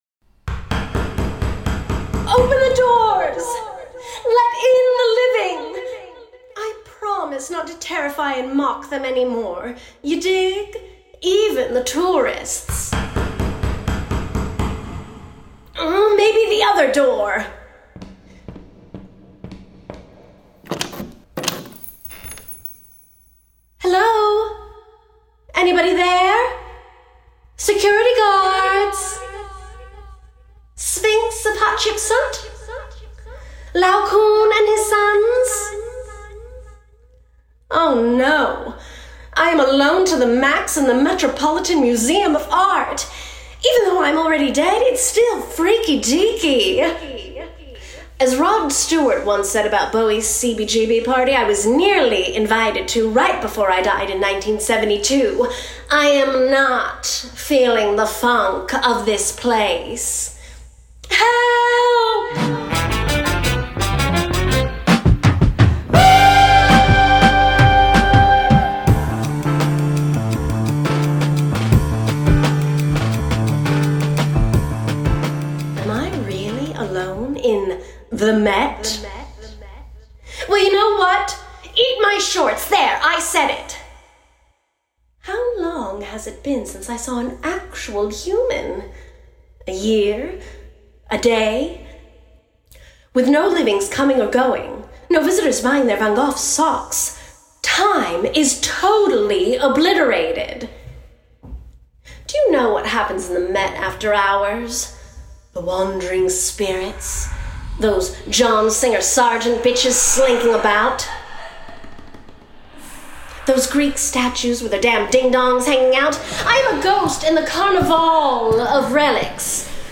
Format: Audio Drama
Voices: Full cast
Narrator: First Person
Genres: Comedy, Occult and Supernatural
Soundscape: Sound effects & music